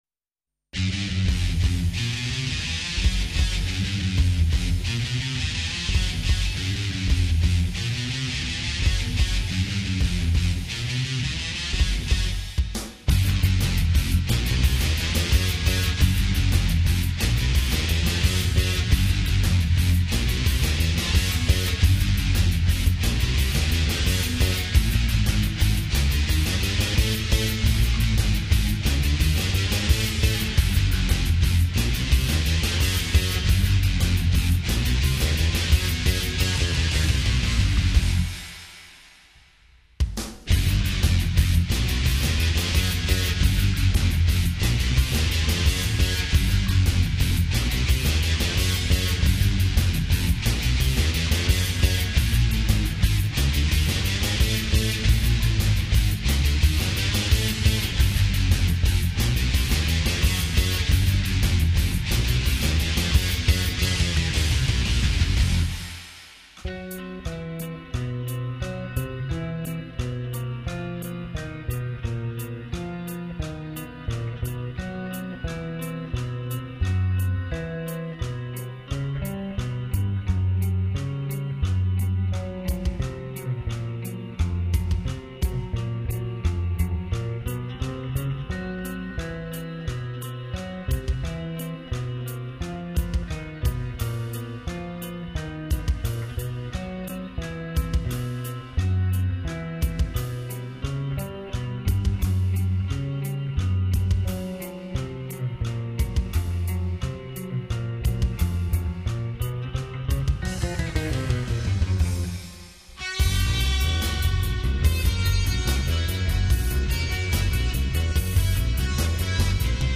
Demo Recordings 1990-2025